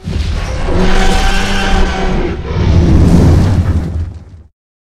taunt.ogg